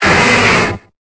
Cri de Lokhlass dans Pokémon Épée et Bouclier.